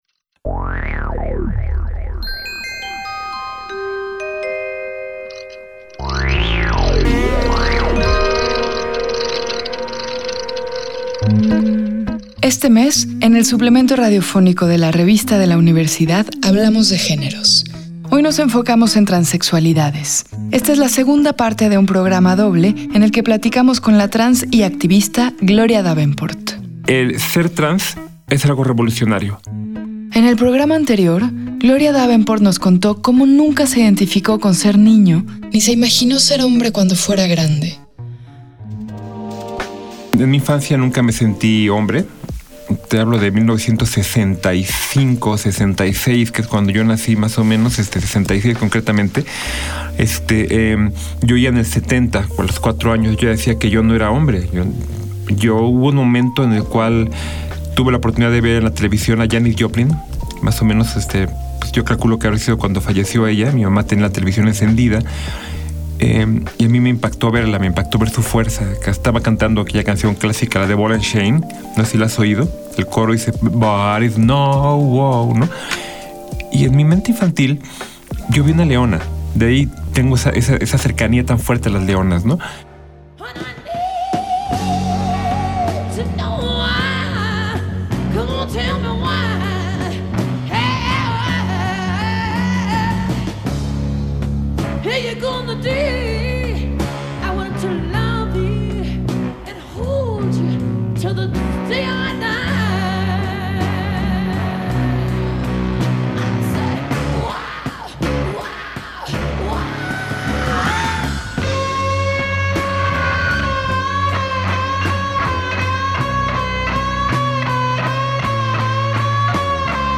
Fue transmitido el jueves 14 de marzo de 2019 por el 96.1 FM.